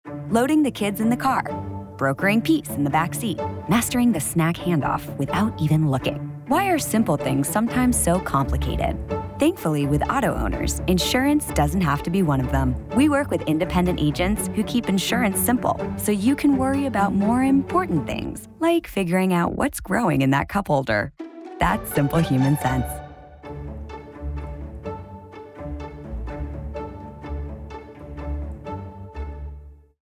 Auto Insurance Radio Ads